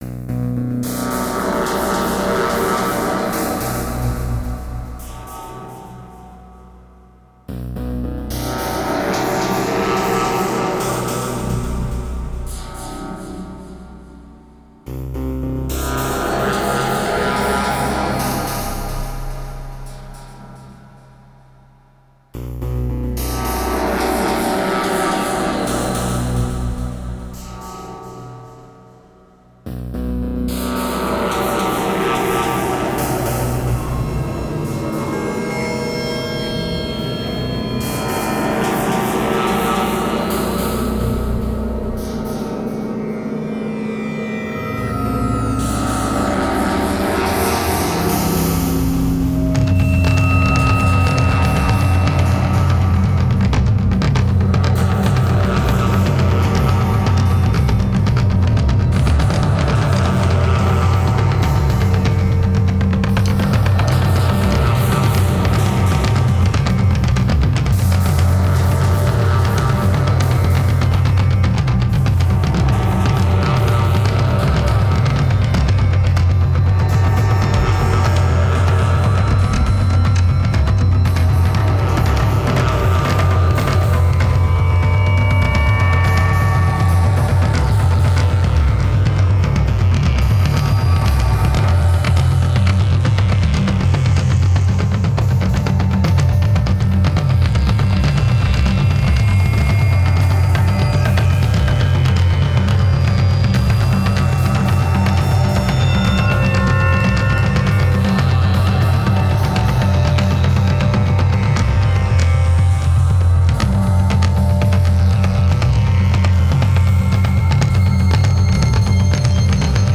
Malstroem Synth, Korg Ms 20, slagverk, mellotron